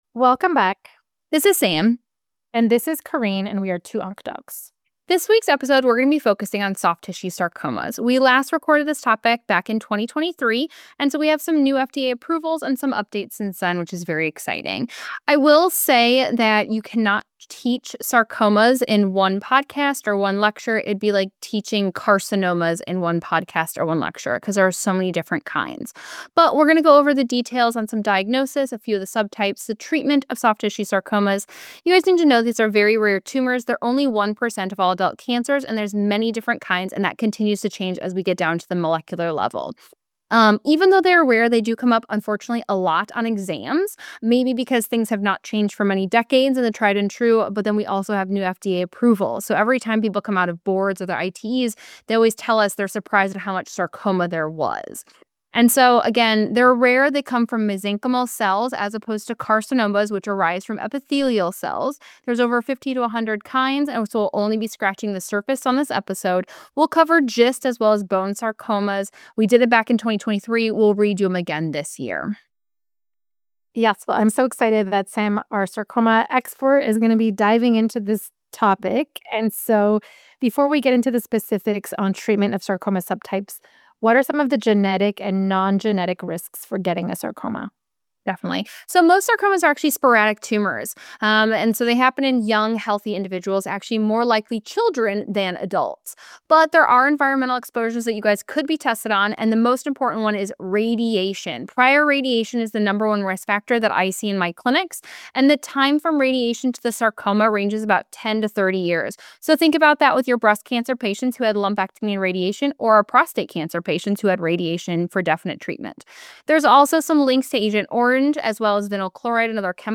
In this episode, OncLive On Air® partnered with Two Onc Docs to feature a conversation about soft tissue sarcoma management.